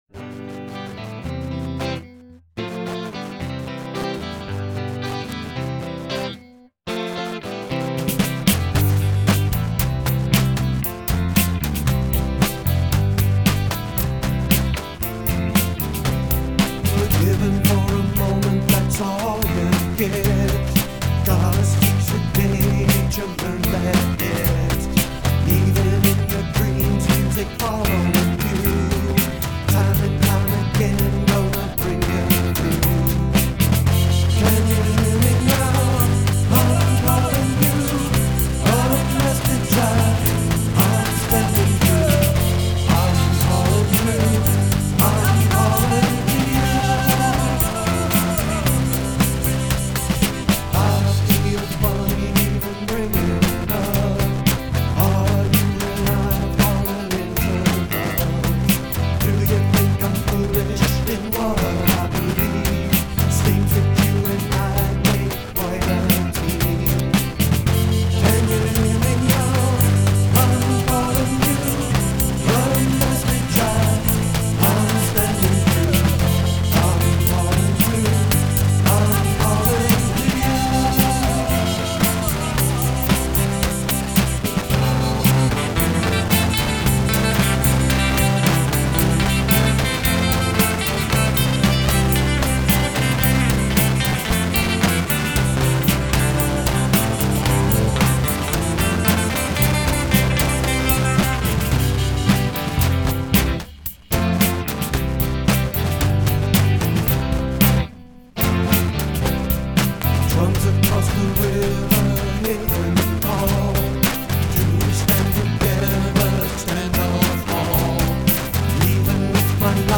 bass
drums
lead vocals